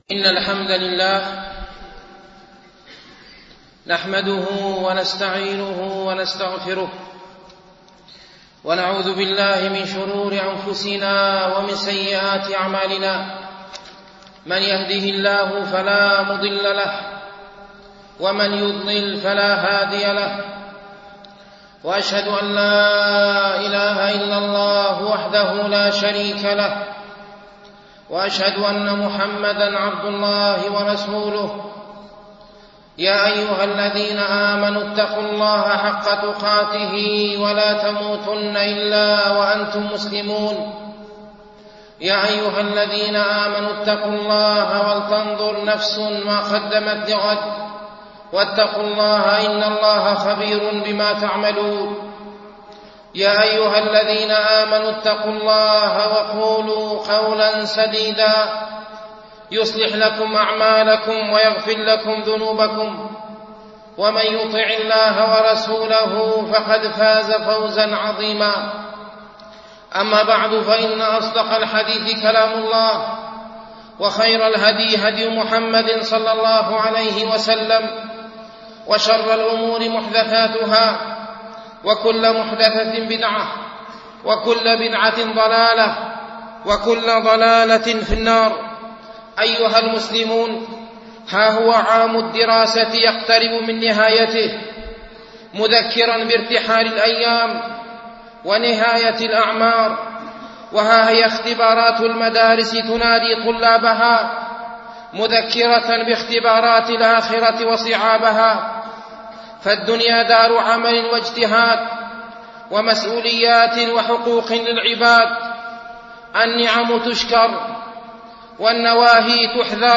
خطب - Page 18 of 923 - موقع دروس الإمارات